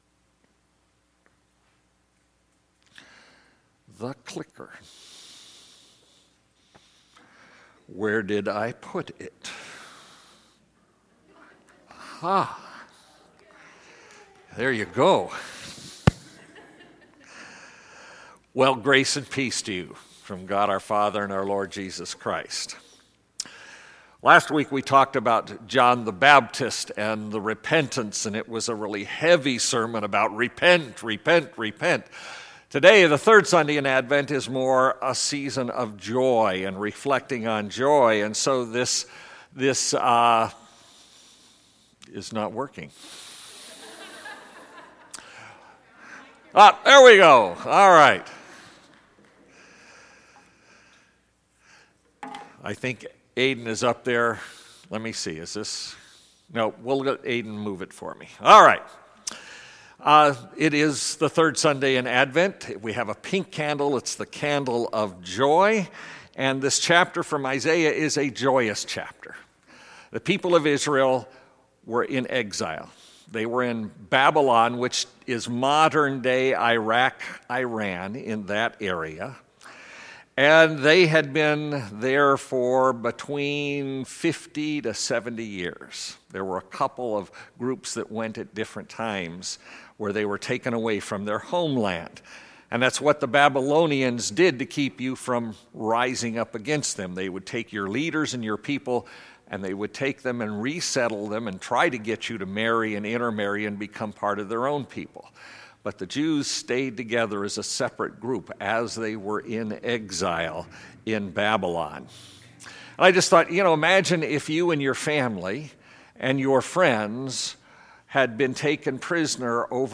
Our Redeemer Lutheran Church Garden Grove Sermons